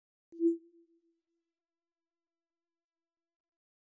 Futurisitc UI Sound 15.wav